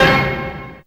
Q ORCH HITHI.wav